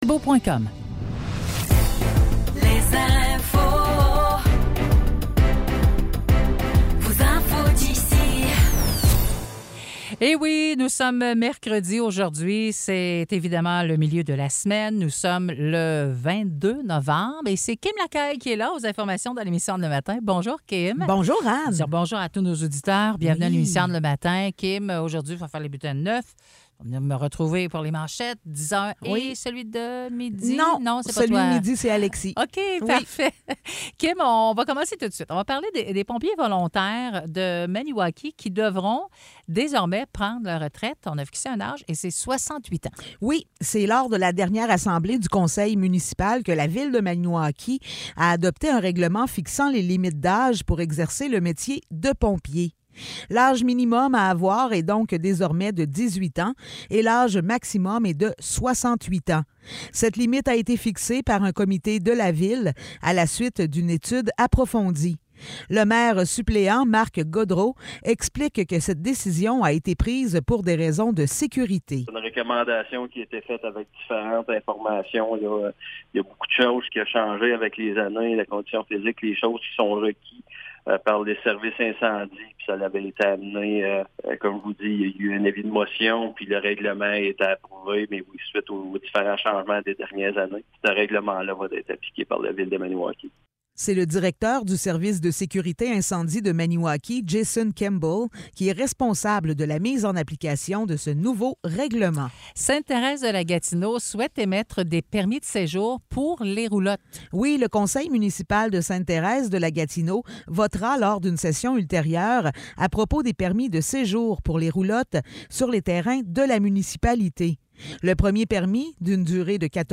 Nouvelles locales - 22 novembre 2023 - 9 h